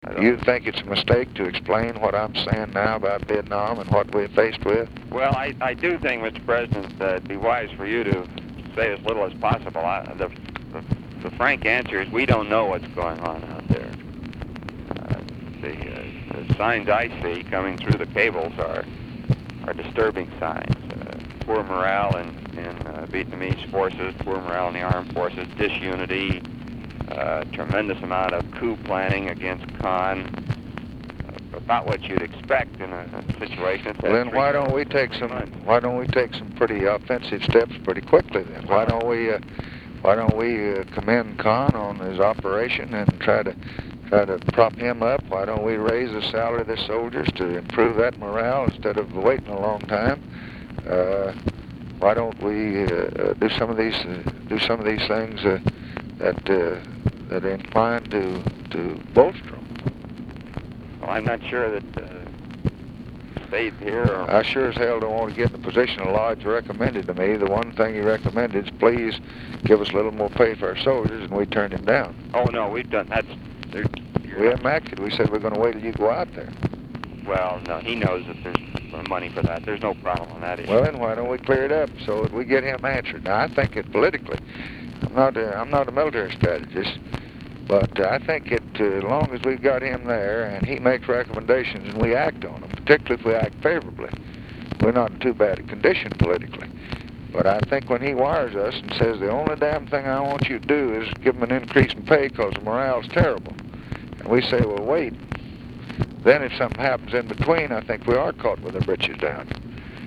Its importance intensified with Lodge’s emergence as a viable candidate in New Hampshire. This clip with Defense Secretary McNamara, from March 2, combined the two elements.